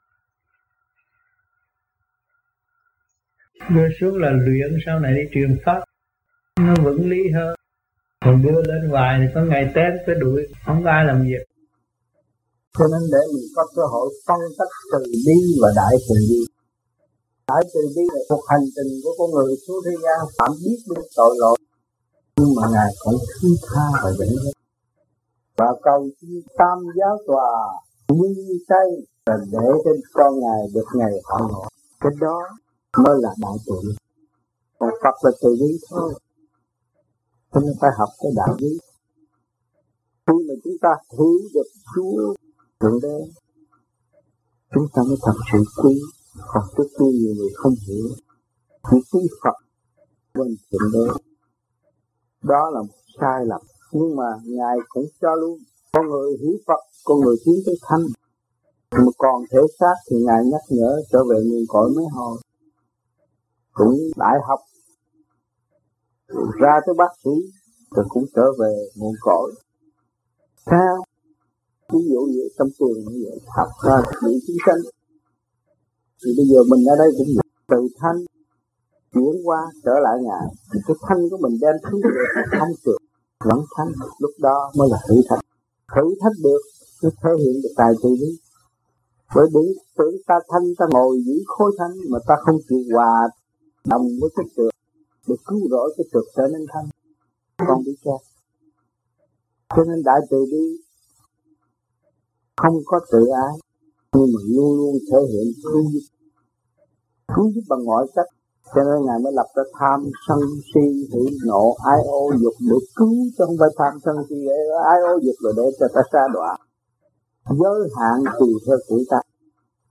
VẤN ĐẠO
THUYẾT GIẢNG